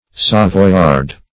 Savoyard \Sav`oy*ard"\, n.